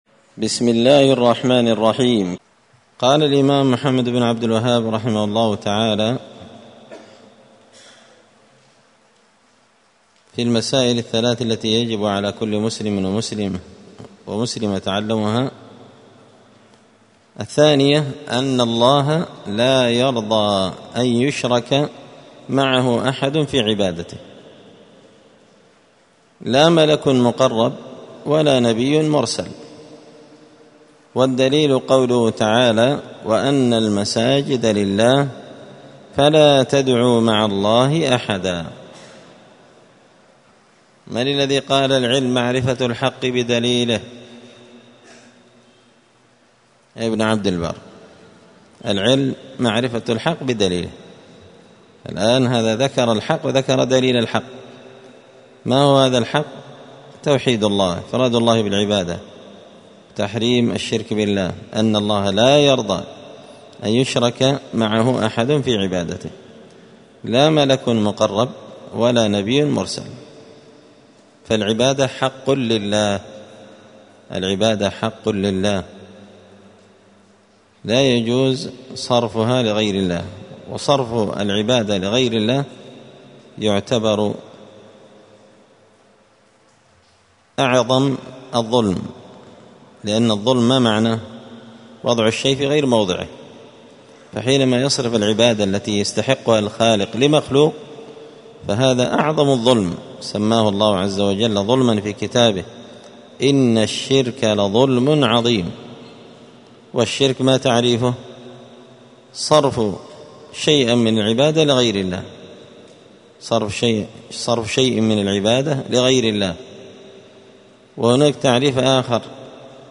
*الدرس السابع (7) من قوله {الثانية أن الله لايرضى أن يشرك معه…}*
7الدرس-السابع-من-كتاب-حاشية-الأصول-الثلاثة-لابن-قاسم-الحنبلي.mp3